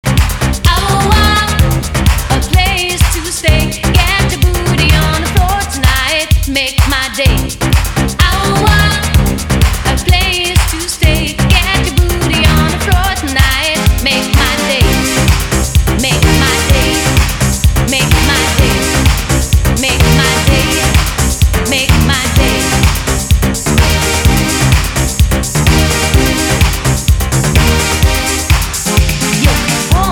• Качество: 320, Stereo
ритмичные
Electronic
Eurodance
hip-house